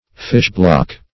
Fish-block \Fish"-block`\, n.